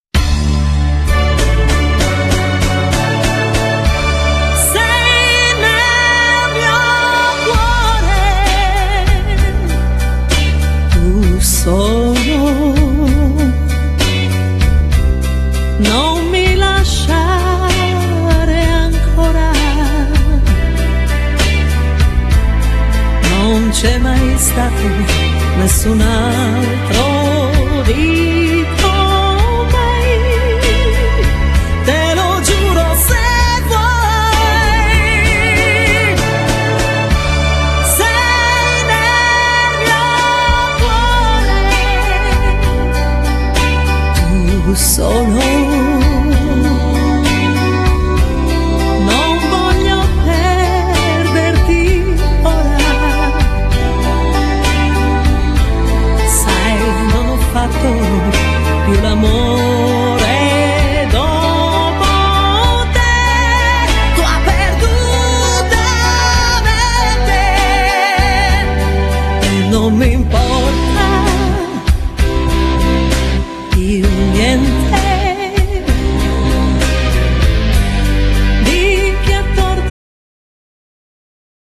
Genere : Liscio Pop